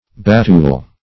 Search Result for " batule" : The Collaborative International Dictionary of English v.0.48: Batule \Bat"ule\ (b[a^]t"[=u]l or b[.a]*t[=oo]l"), n. A springboard in a circus or gymnasium; -- called also batule board .